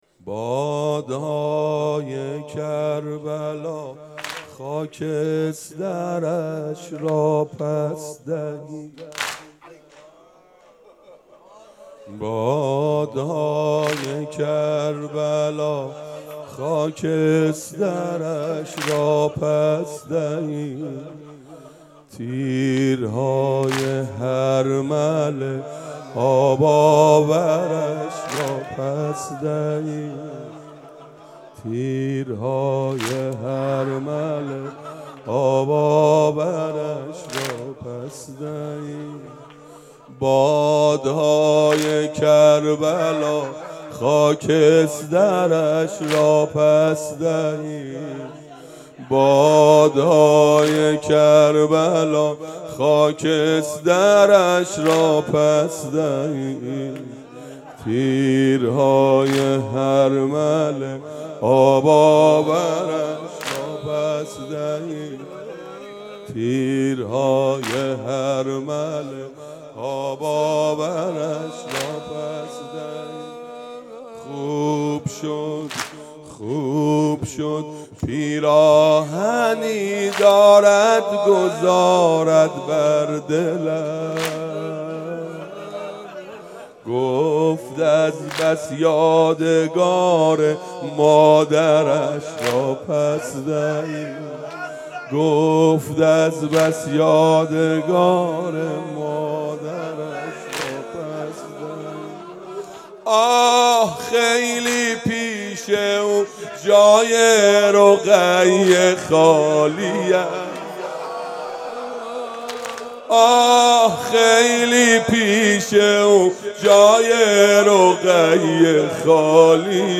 شب سوم - اربعین 97 - واحد - بادا یه کربلا خاکسترش را